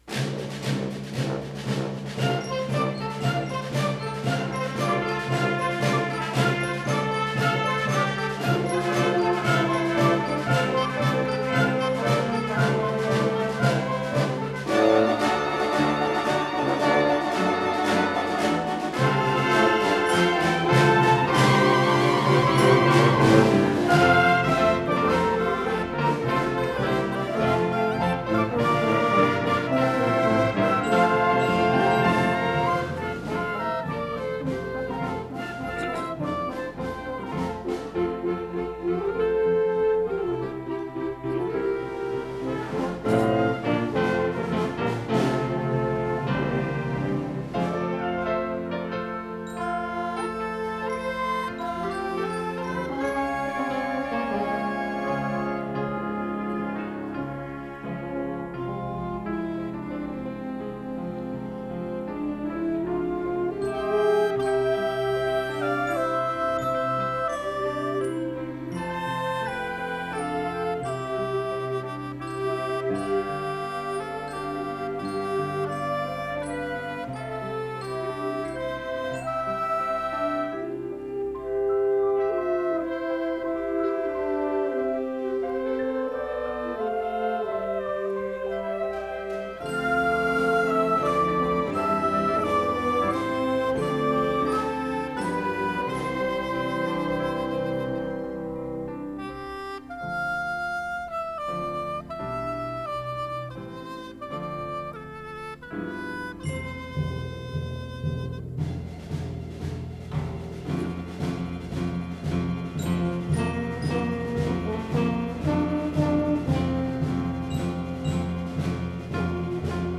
Joy To The World played by the Faith Baptist Orchestra 2012
joy-to-the-world-faith-baptist-orchestra-dec-2012.mp3